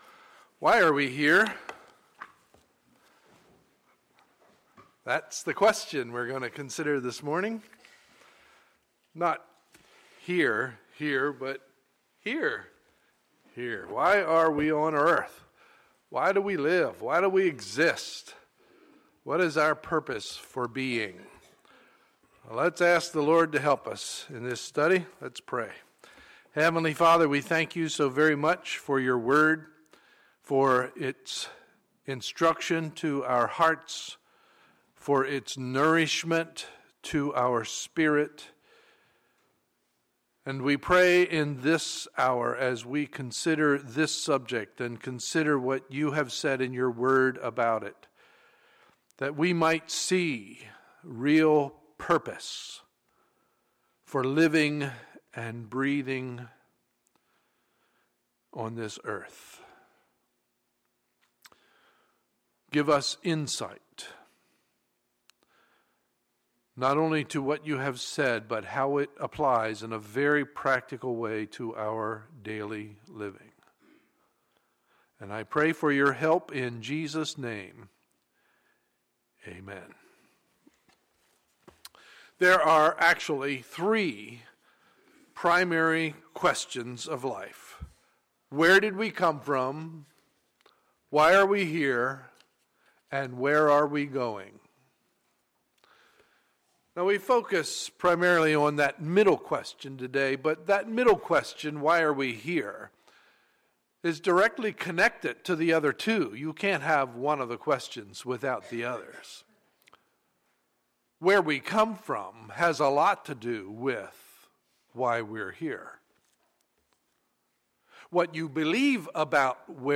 Sunday, September 28, 2014 – Sunday Morning Service